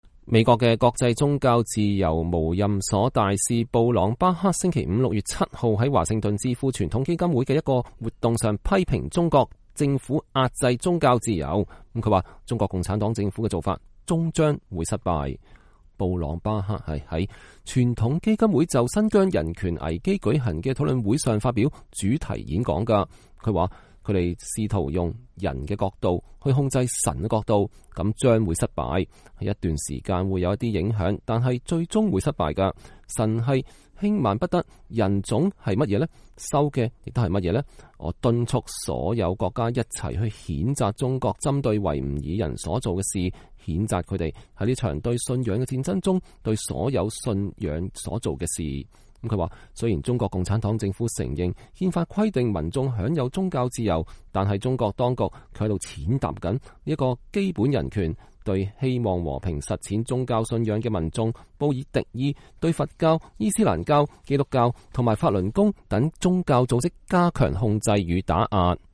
美國的國際宗教自由無任所大使布朗巴克（Sam Brownback）星期五（6月7日）在華盛頓智庫傳統基金會的一個活動上批評中國壓制宗教自由。